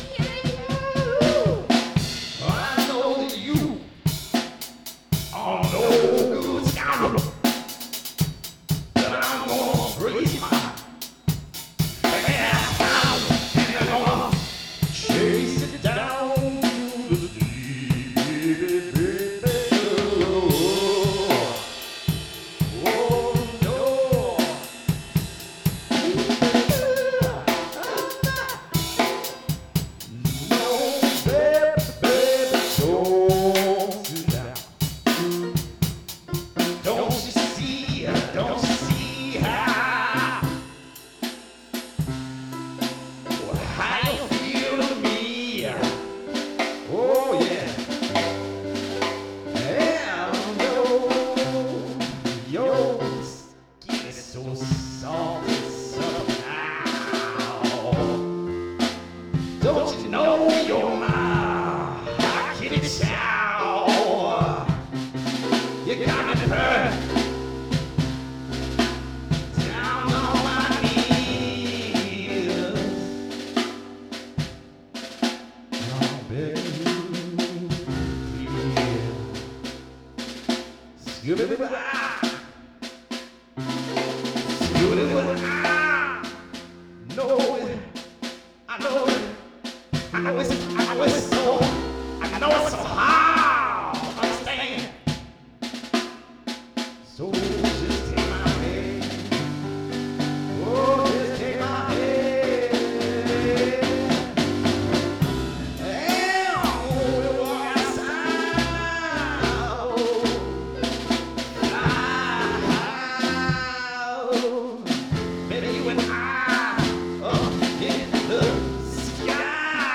Blues In C